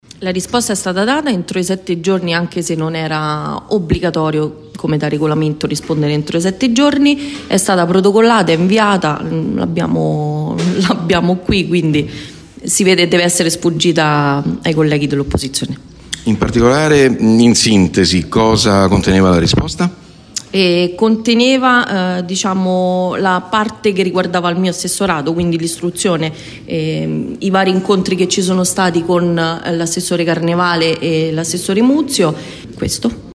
Per Tesone però, la risposta è stata data nel tempo di sette giorni: “Deve essere sfuggita ai colleghi dell’opposizione”, ha dichiarato in conferenza stampa.